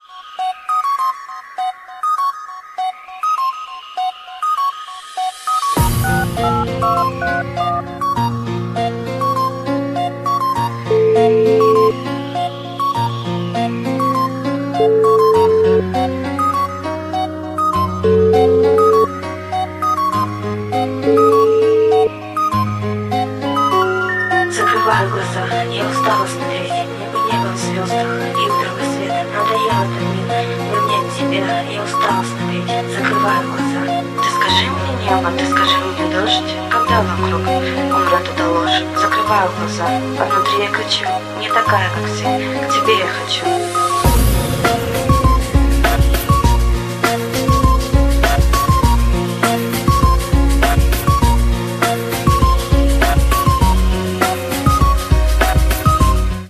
• Качество: 192, Stereo
ритмичные
грустные
beats